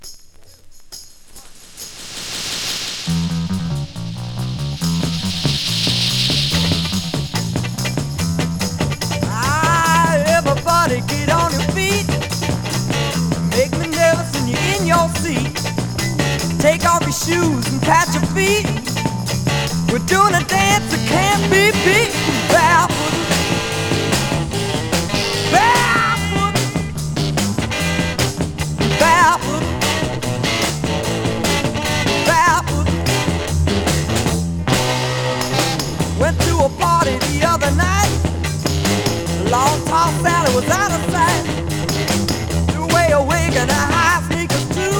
サウンドは荒々しく、まさにダイナマイト。実験的とも思えるサイケデリックさも有り。
Rock, Garage, Psychedelic　USA　12inchレコード　33rpm　Stereo